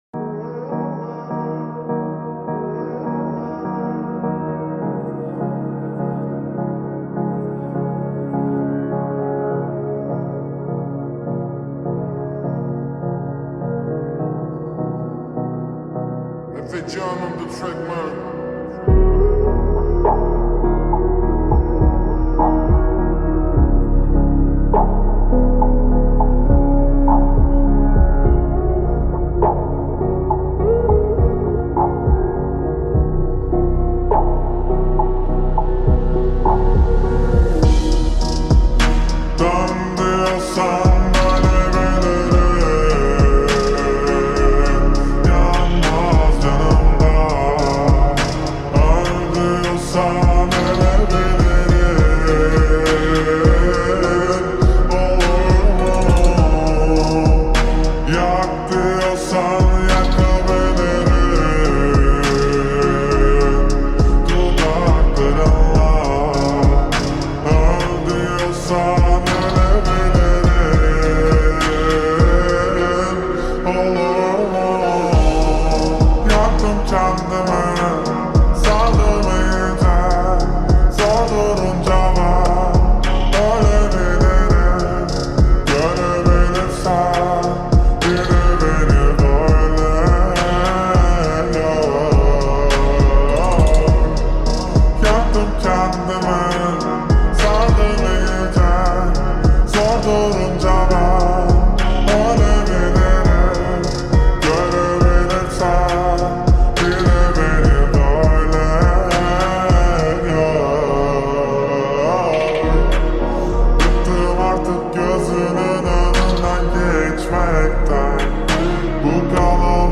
ورژن آهسته کاهش سرعت